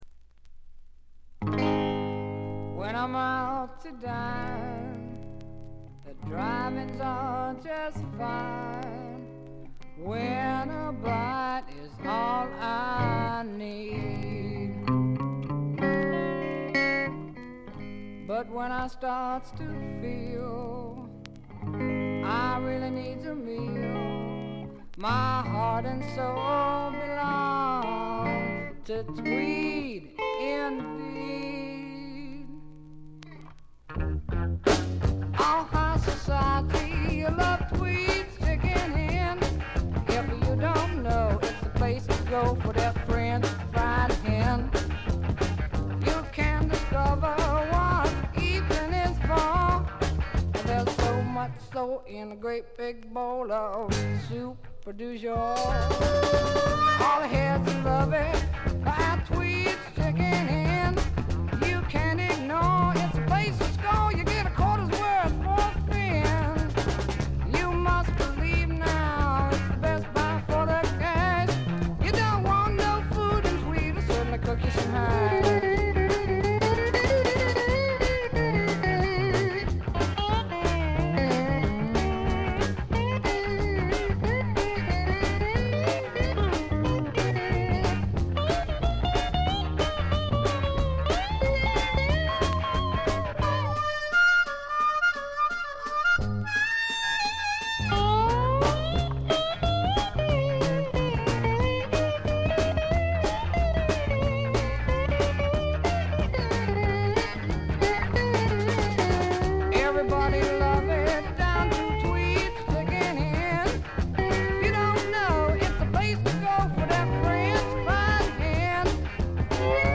ほとんどノイズ感無し。
個人的にはギターの音色がなかなかアシッド／サイケしていてかなりつぼに来ます。
試聴曲は現品からの取り込み音源です。